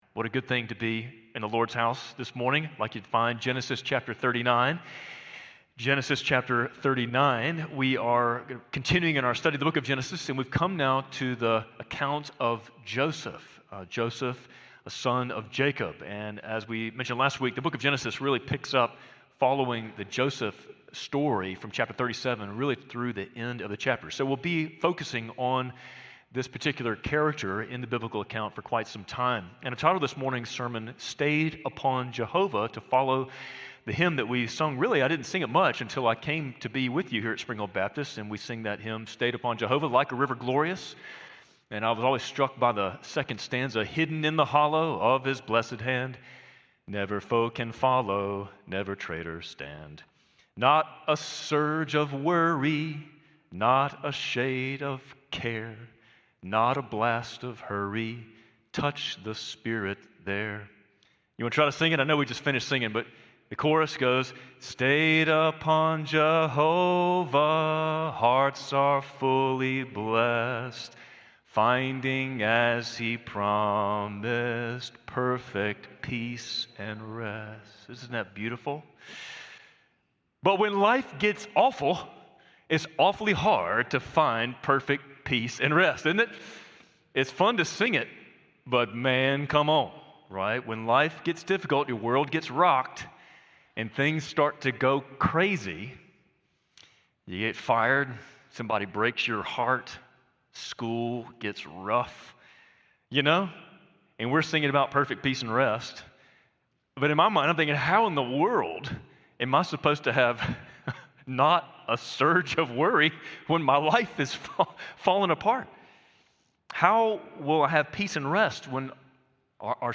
Spring Hill Baptist Sunday Sermons (Audio) / From Pit to Prison